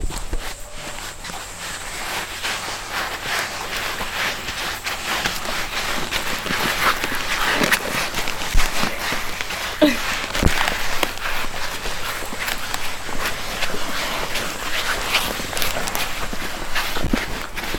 모래걸음.mp3